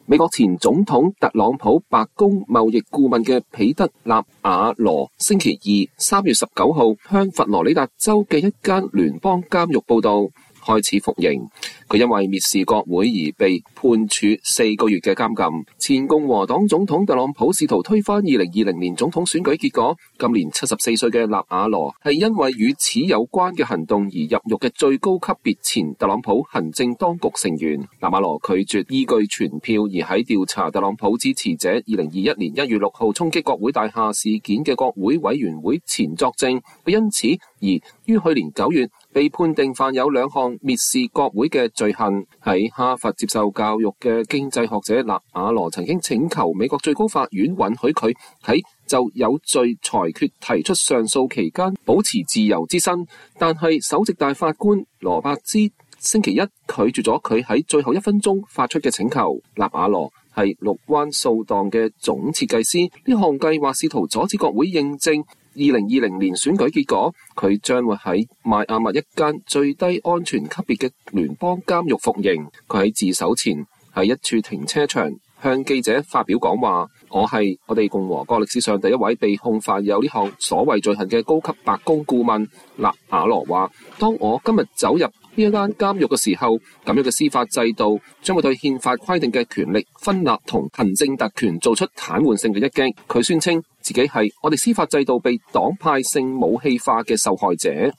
前白宮貿易顧問納瓦羅在前往監獄服刑前在邁阿密對媒體講話。(2024年3月19日)
他在自首前在一處停車場向記者們發表了講話。